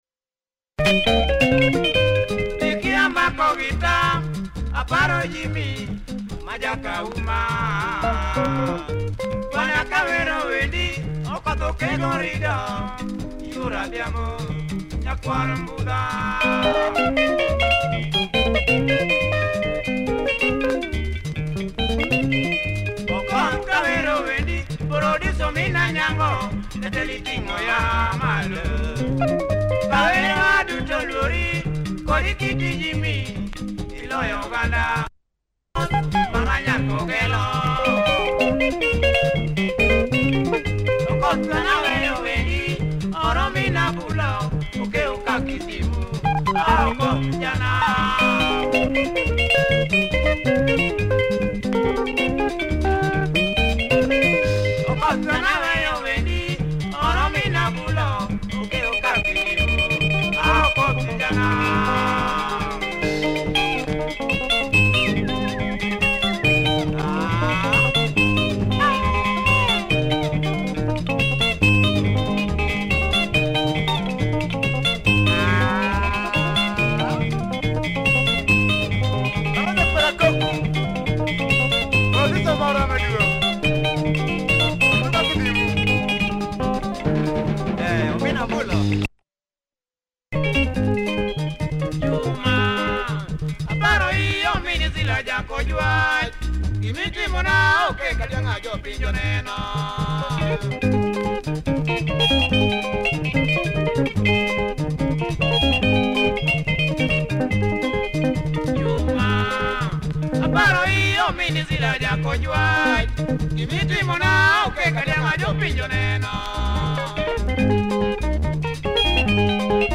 Great luo benga, good vibe and drive throughout.